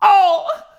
OUH.wav